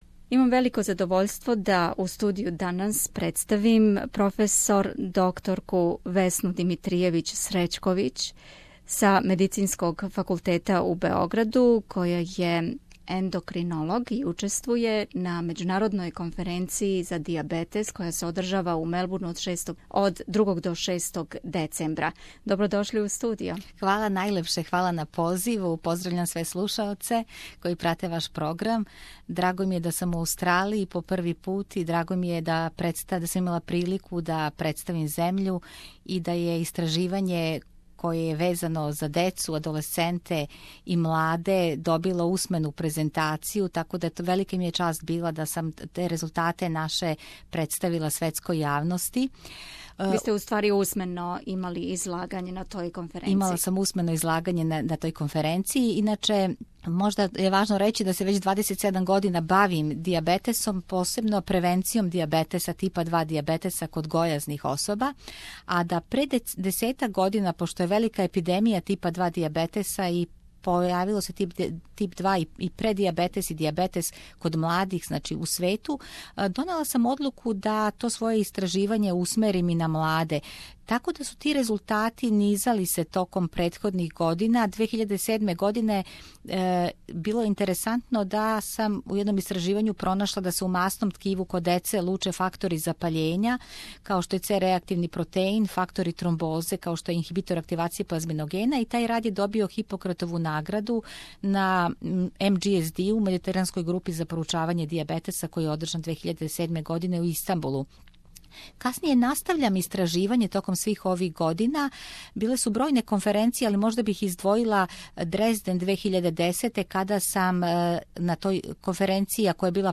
Она је била гост у СБС студију и рекла нам више о предавању које је одржала на конгресу и њеним научним открићима.